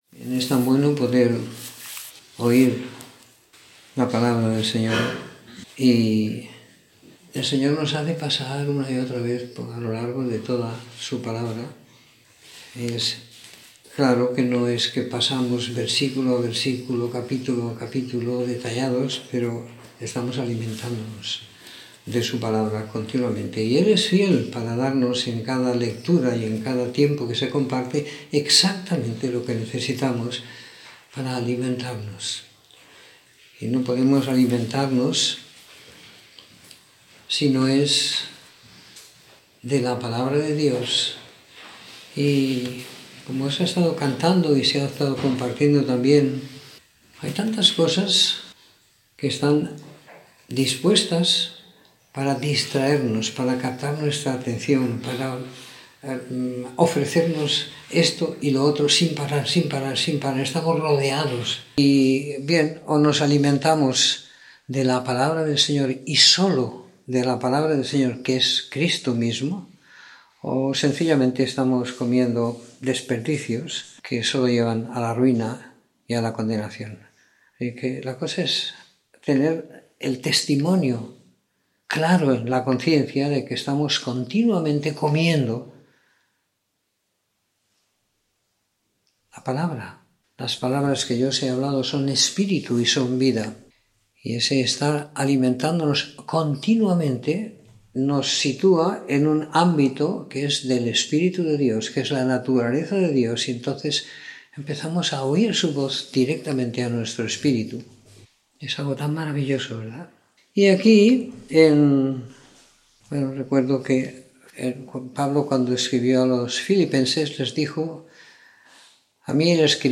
Comentario en el libro de Hechos del capítulo 13 al 28 siguiendo la lectura programada para cada semana del año que tenemos en la congregación en Sant Pere de Ribes.